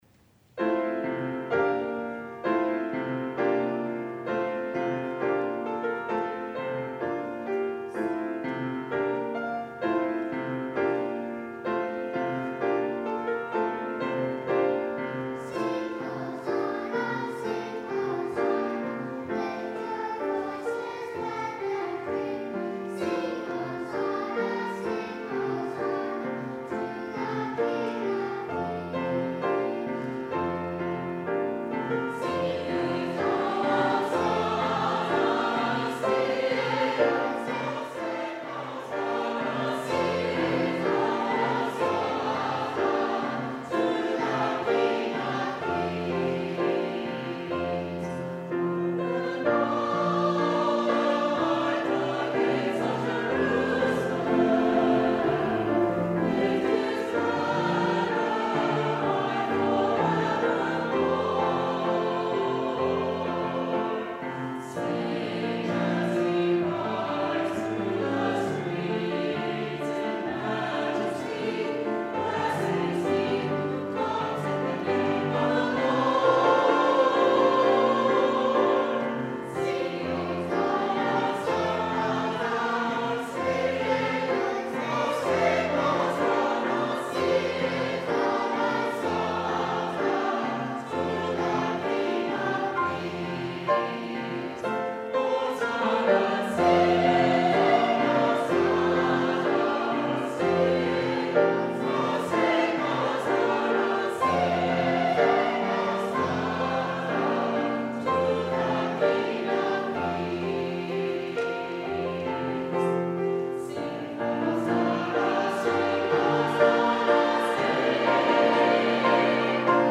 Chancel & Junior Choirs
piano